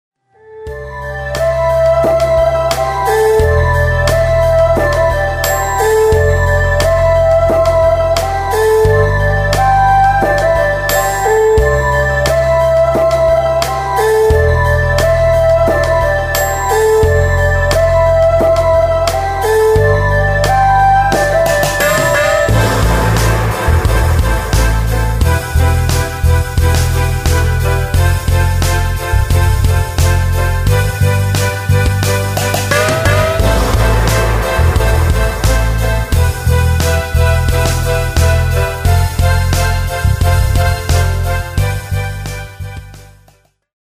爲了節省說明時間, 我製作的這段是從歌曲的間奏開始, 之後接歌曲的副歌.
1. China Percussion: 前奏第二段, 副歌前一小節, 第一拍下
2. Reverb Rhythm Loop + 三角鐵: 前奏第一段, 主歌和間奏第一小節第一拍下
這首歌 Tempo 約 88 BPS.
• 鼓, Bass 音源: Roland Fantom X8,
• 中國銅鈸: SRX-09 World Collection
• 二胡音源: mini ErHu (Free)
• Loop: Roland Drum Kits + Sonar 後製
沒有做的很精緻, 只是想呈現一個想法, 套在樂團的編製上是可行的.